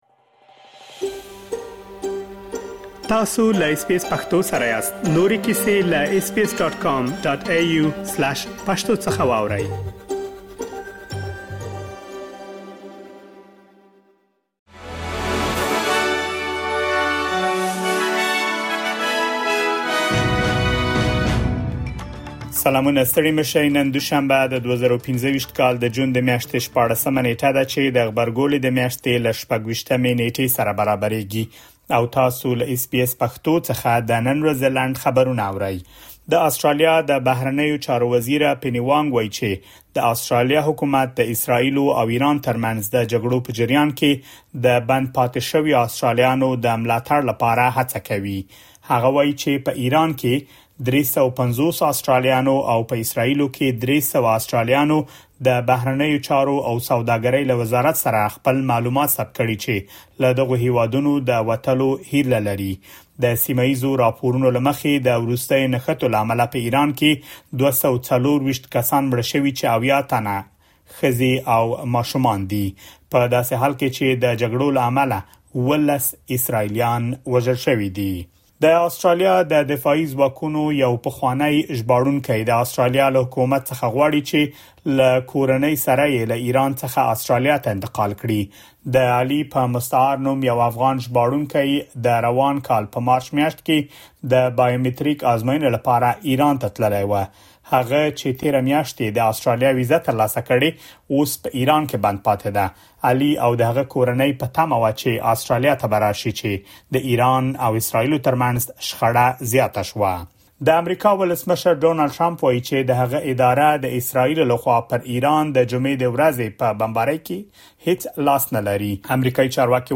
د اس بي اس پښتو د نن ورځې لنډ خبرونه | ۱۶ جون ۲۰۲۵